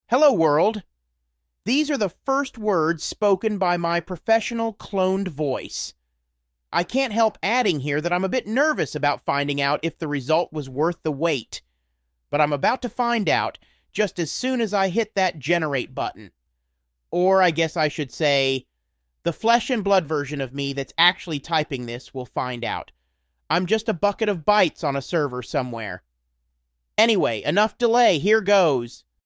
My professionally cloned voice has just become available to me. And here's the very first thing it ever said.